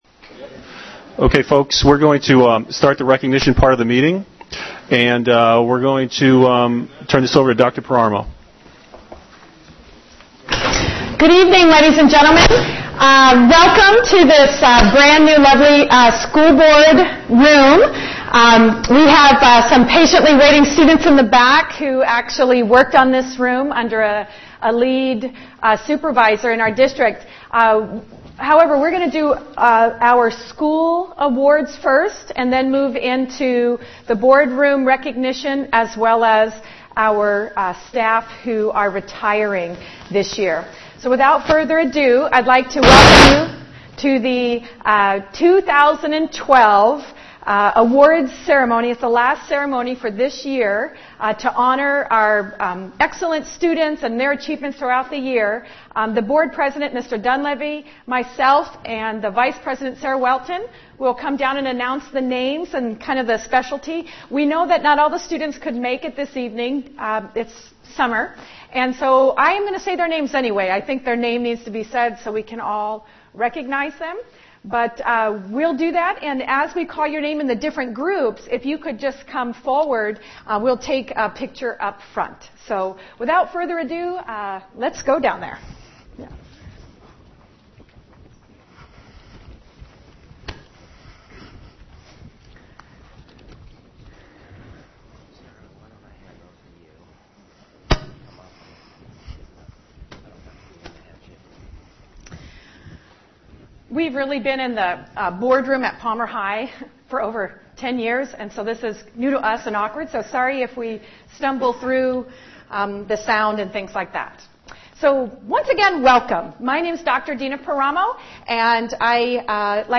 School Board Meeting June 6, 2012
Students’ successes and retiring teachers contributions to the district were recognized by the School District beginning at 5 PM. The regular School Board meeting began at 6 PM.